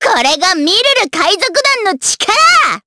Miruru-Vox_Skill3_jp.wav